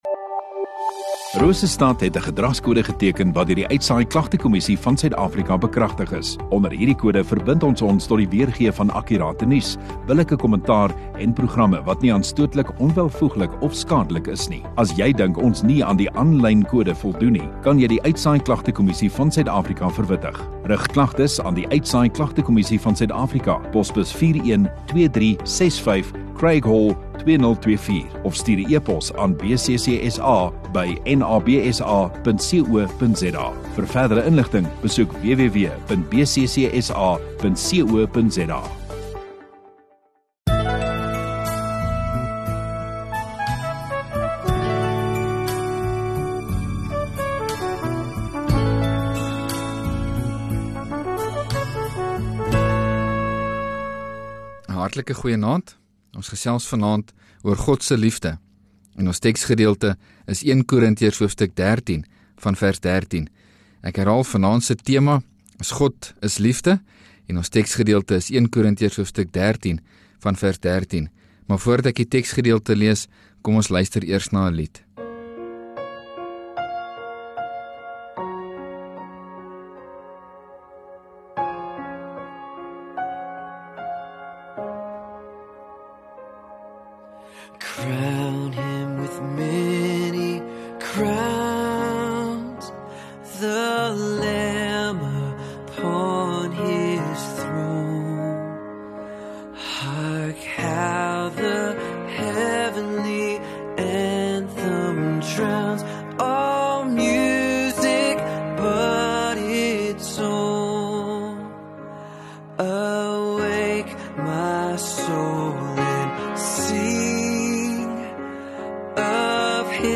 10 Mar Sondagaand Erediens